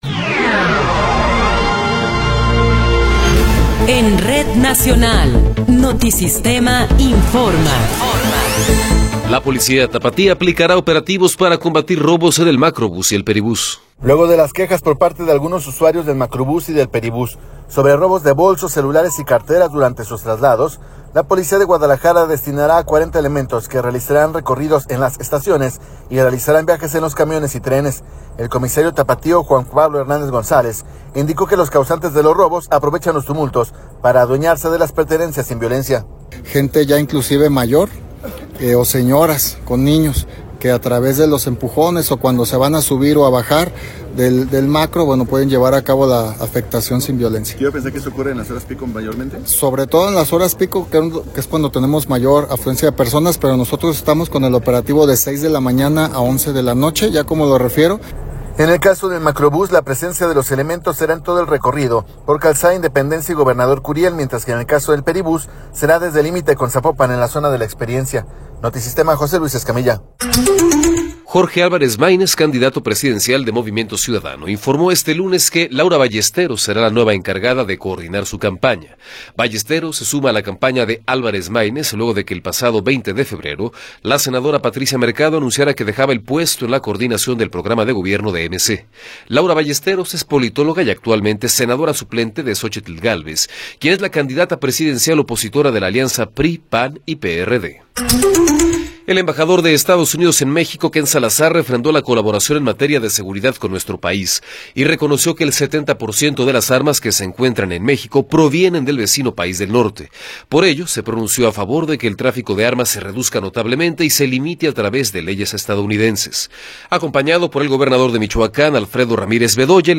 Noticiero 12 hrs. – 26 de Febrero de 2024
Resumen informativo Notisistema, la mejor y más completa información cada hora en la hora.